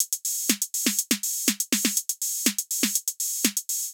AIR Beat - Perc Mix 3.wav